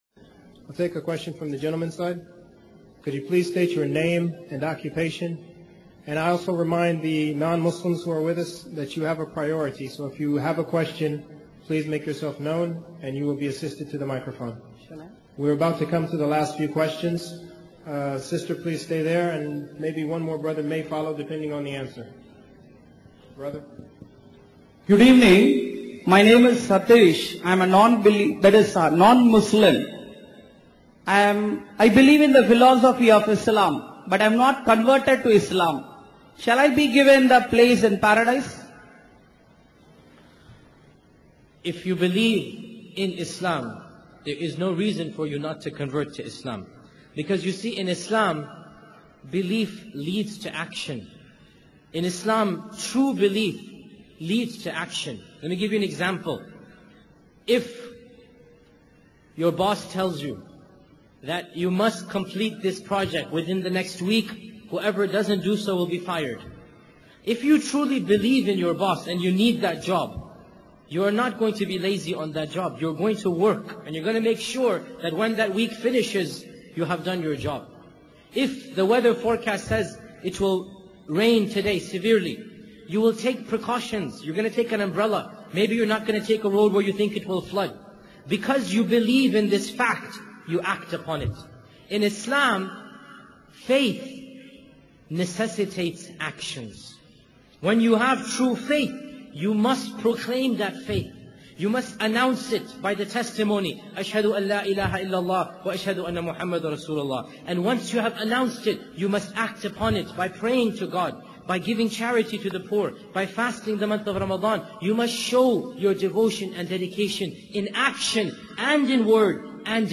• He raised his hand and came to the stage, where he recited the shahada in front of the entire audience